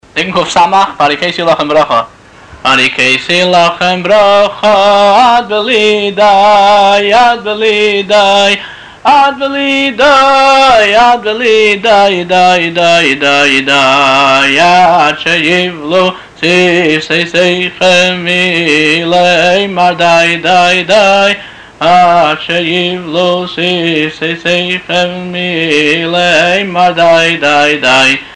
לשמיעת הניגון מאת הבעל-מנגן